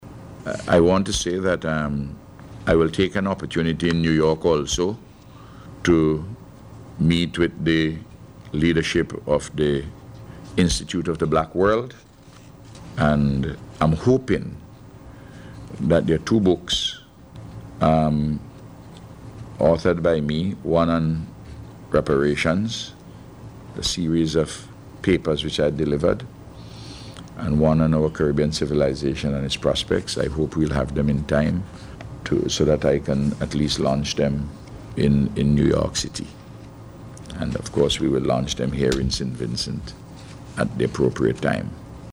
The Prime Minister told reporters on Monday that while in Brooklyn, he will use the opportunity to meet with several officials